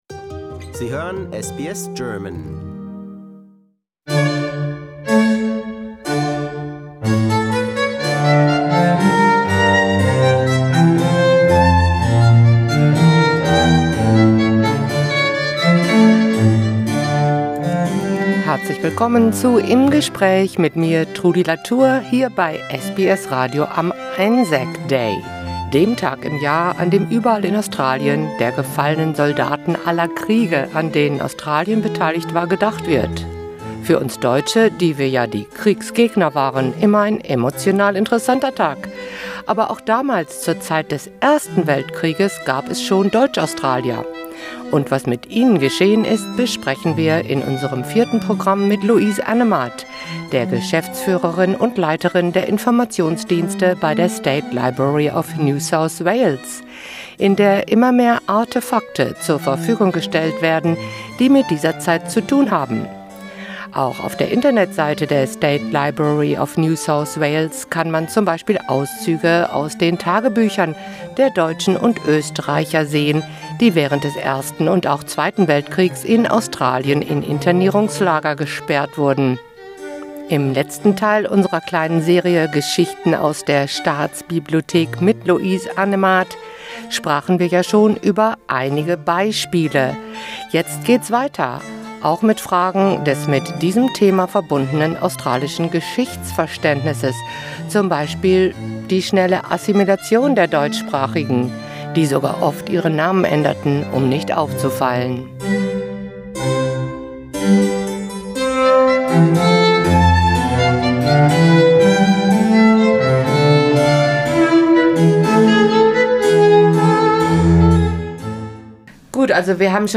In einer kleinen Sommerserie präsentiert SBS German ausgewählte Highlights aus unserem Programm der letzten 12 Monate. Heute: Ein Gespräch